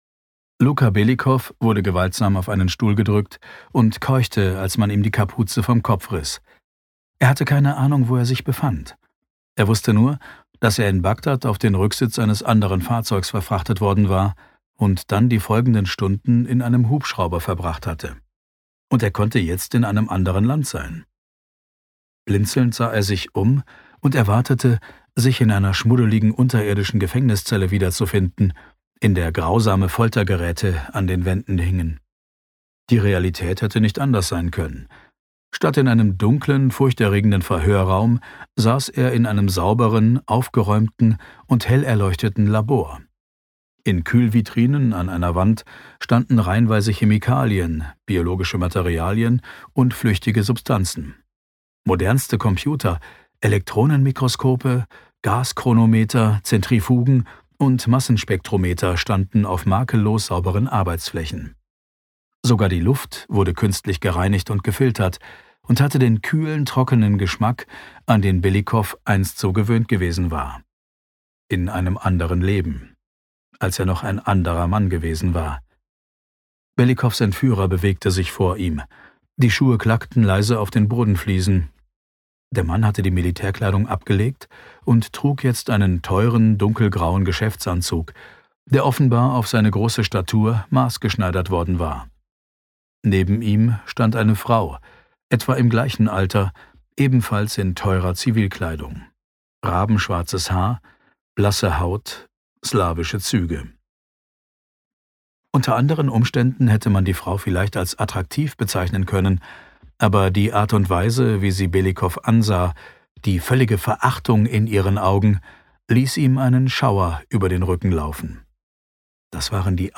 Schlagworte Angriffsziel Circle • Belletristik • Biowaffe • Epidemie • Hörbuch • hörbuch audible • hörbuch empfehlung • Hörbuch kaufen • Hörbuch Thriller • Infektion • Mission Vendetta • outbreak • Ryan Drake • science thriller • Seuche • Spannung • Terror • Terroristen • Thriller • thriller autoren • Thriller Bestseller • Thriller Bücher • tödliches Virus • WHO